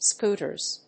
/ˈskutɝz(米国英語), ˈsku:tɜ:z(英国英語)/